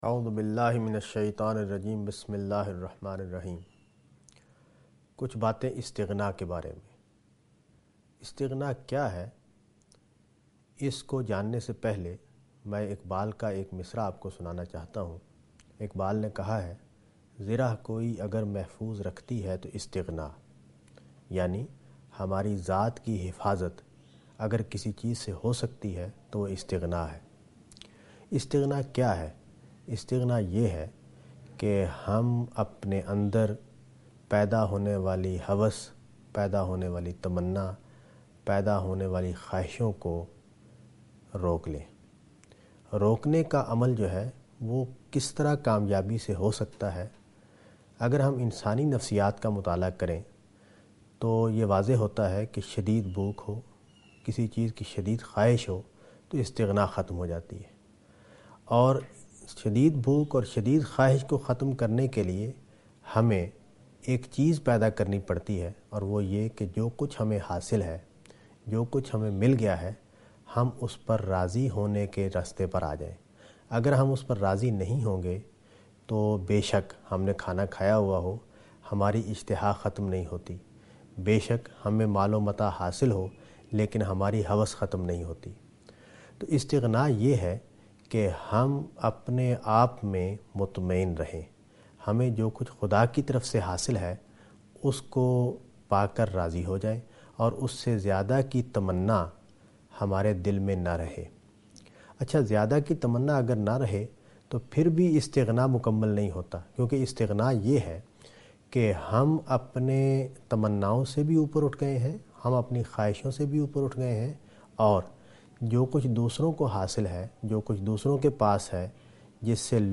A short talk
ایک مختصر گفتگو